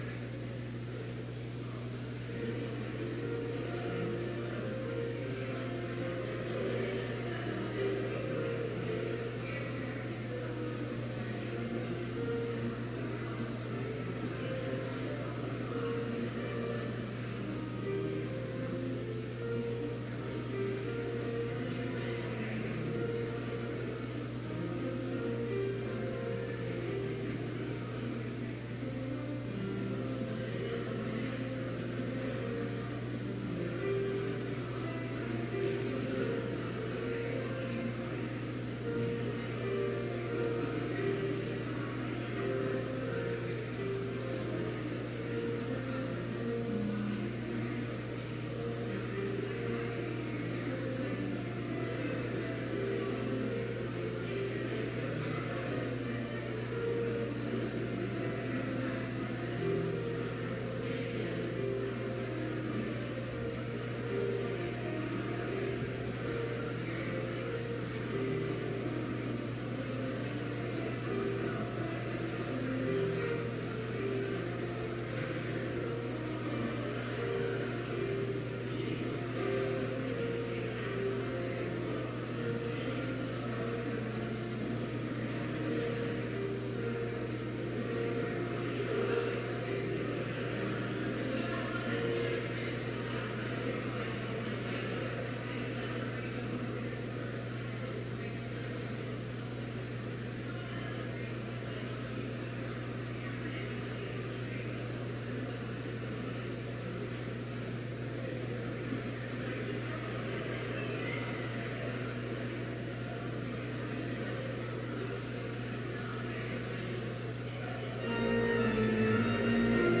Complete service audio for Chapel - October 24, 2018
Sermon